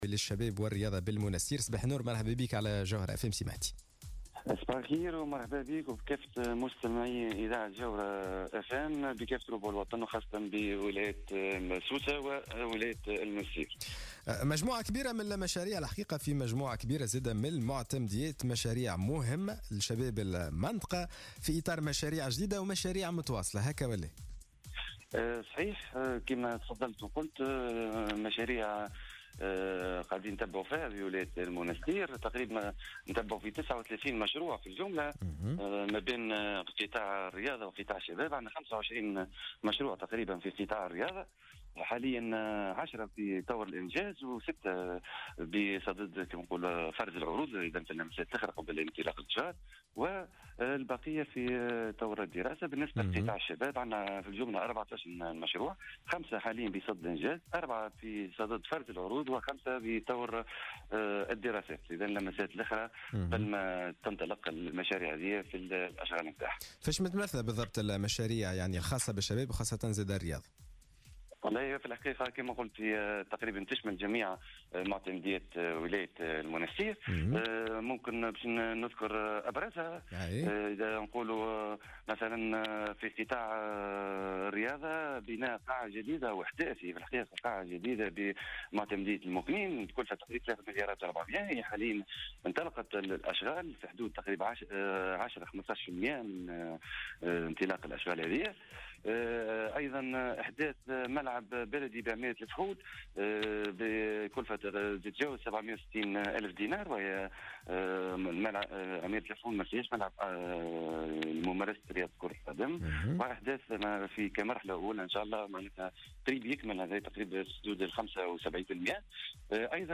أكد المندوب الجهوي للشباب والرياضة بالمنسيتر مهدي الغضاب في مداخلة له في صباح الورد اليوم الجمعة 24 نوفمبر 2017 أن المندوبية تقوم بمتابعة حوالي 39 مشروعا لفائدة قطاعي الشباب والرياضة بكافة معتمديات الجهة.